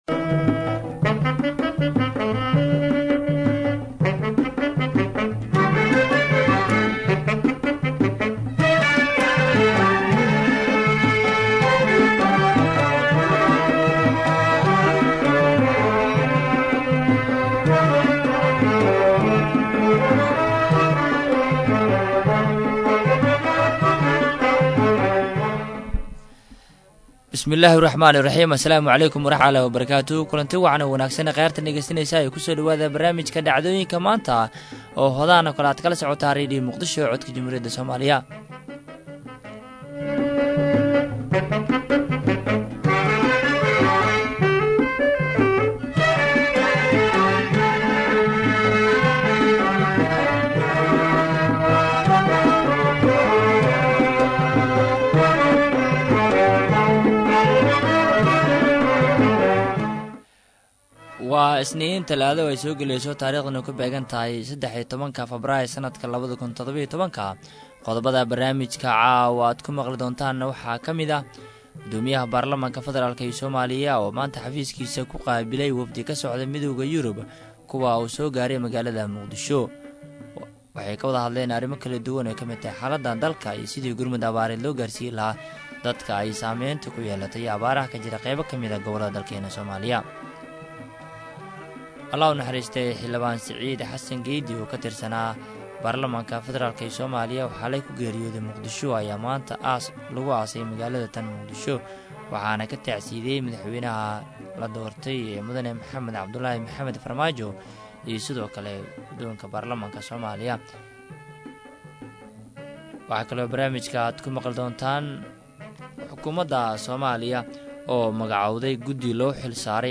Barnaamijka waxaa diirada lagu saaraa raad raaca ama falanqeynta dhacdooyinka maalintaas taagan, kuwa ugu muhiimsan, waxaana uu xambaarsan yahay macluumaad u badan Wareysiyo.